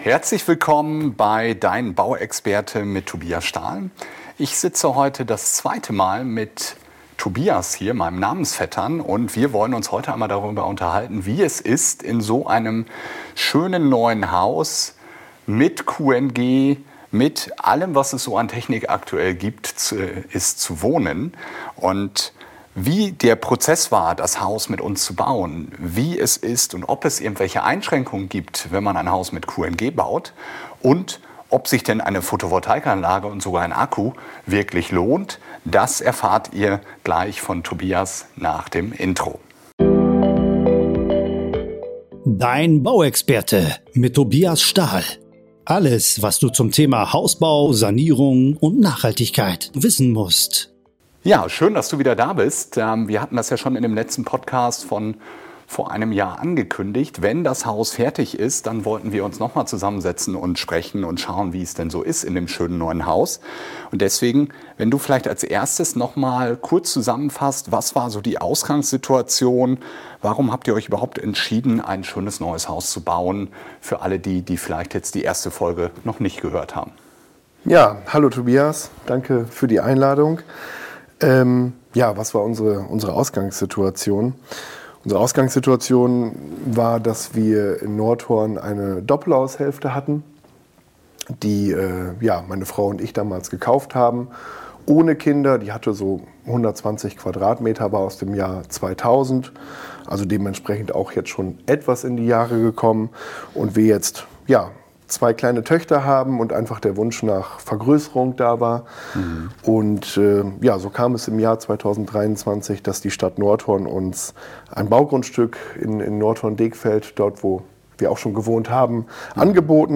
Voller Erfolg beim Hausbau: Interview